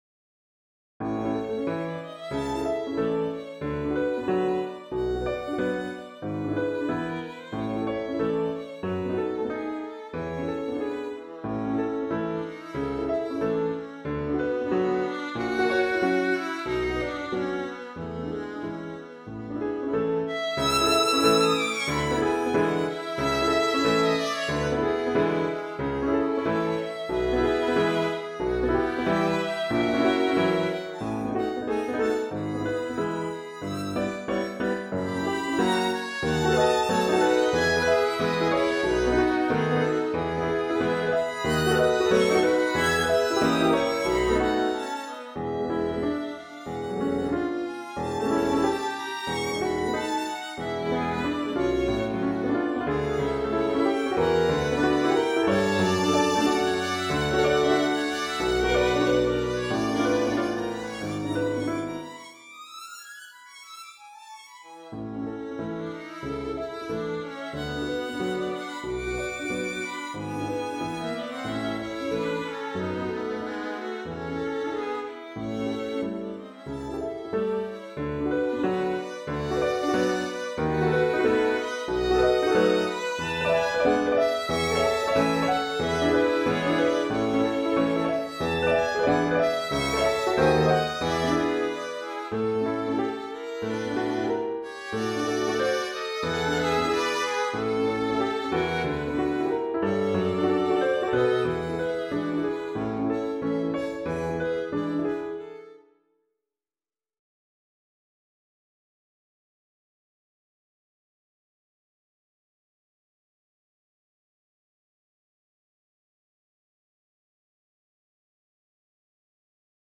【編成】ピアノ三重奏（Violin, Viola, Piano)